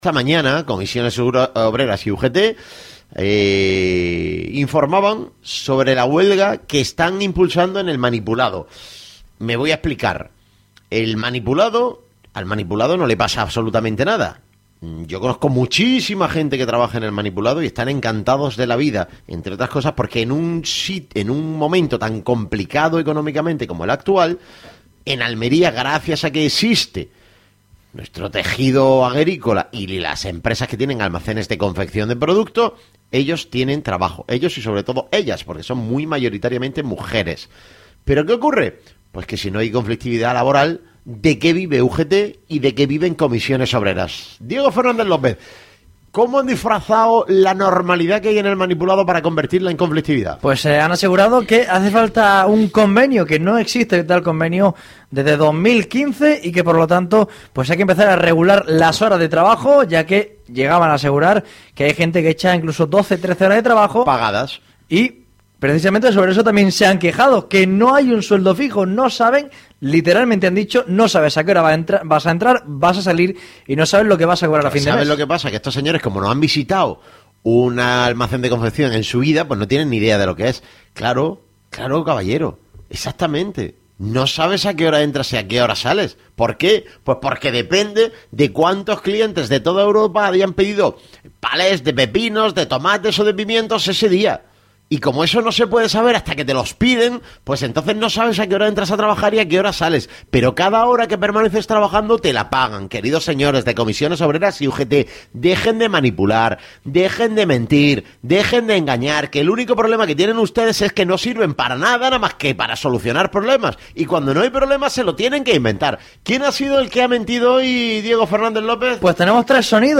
Fragmento-tertulia-Esradio-sobre-la-huelga-del-amnipulado-hortofruticola.mp3